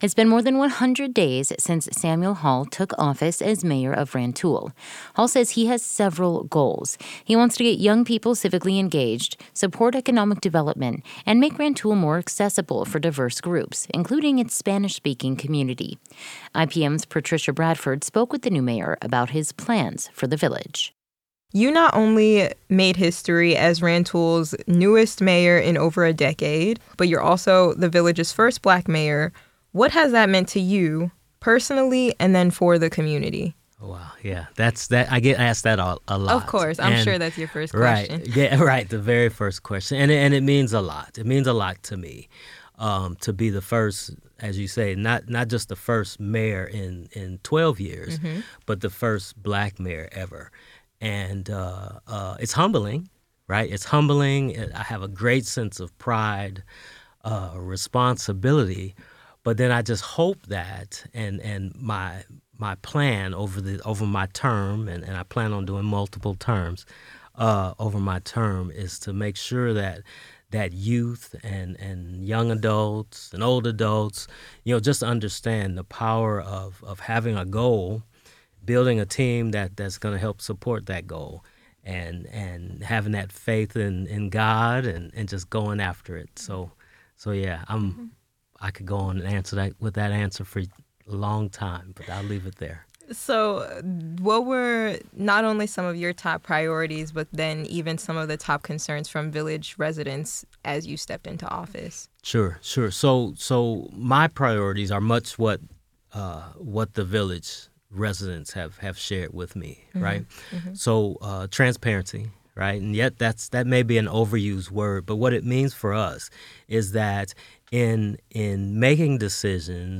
Samuel-Hall_Interview.mp3